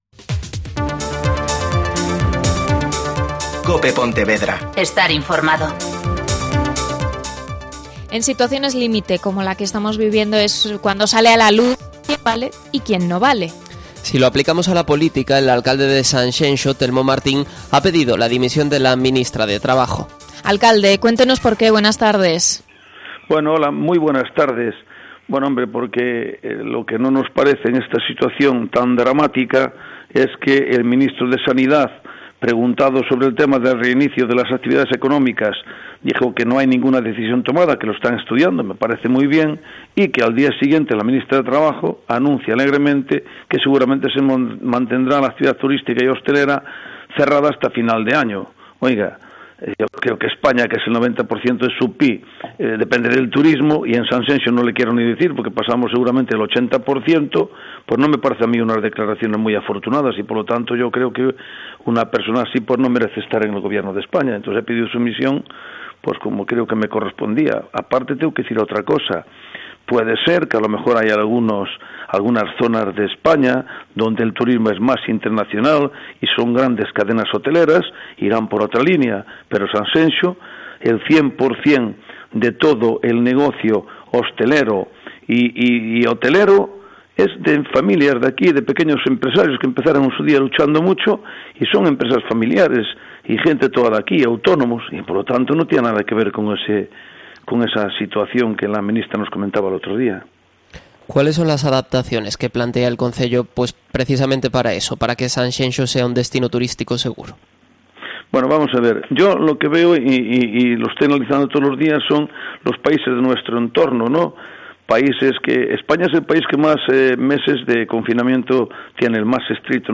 Entrevista sobre turismo al alcalde de Sanxenxo